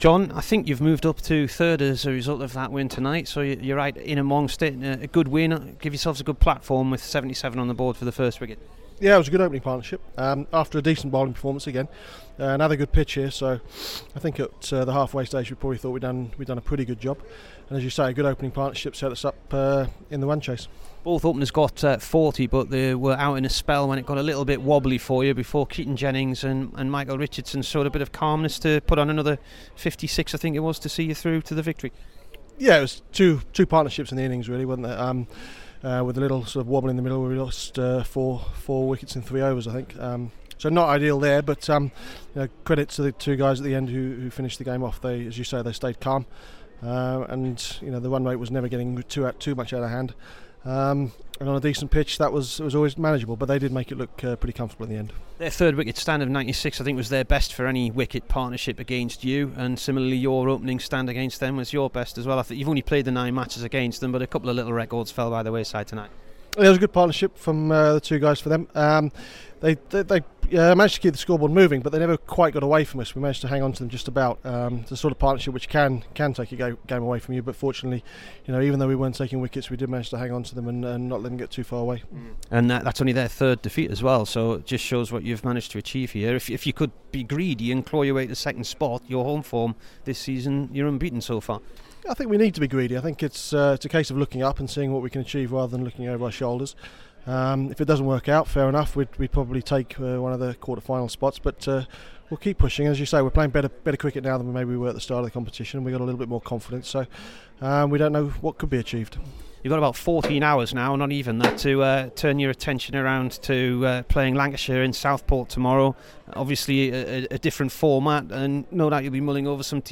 JON LEWIS INT
HERE IS THE DURHAM COACH AFTER THE SIX WICKET WIN AGAINST NORTHANTS IN THE T20.